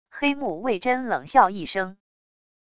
黑木为桢冷笑一声wav音频